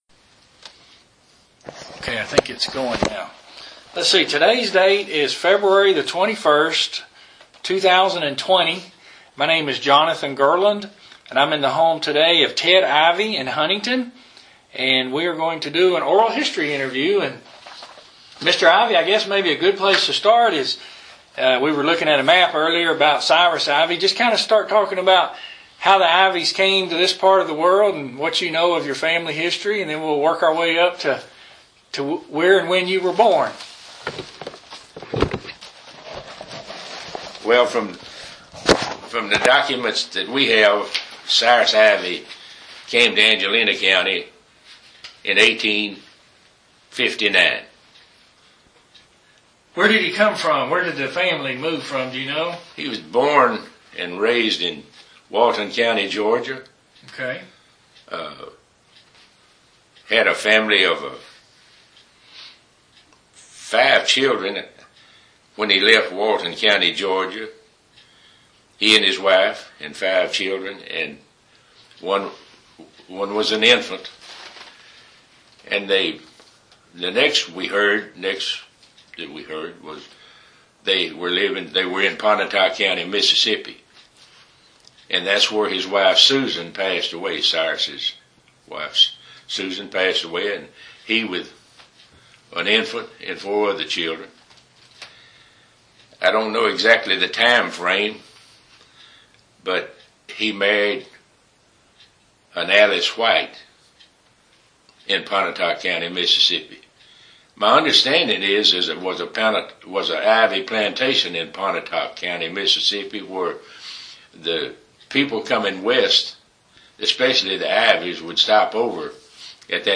Interview 293a